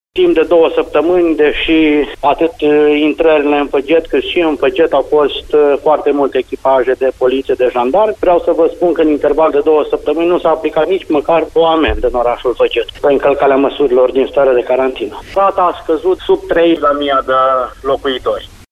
În plus, rata de transmitere a virusului a scăzut semnificativ: doar două noi cazuri au fost raportate ieri, spune primarul Marcel Avram.
2.Marcel-Avram.mp3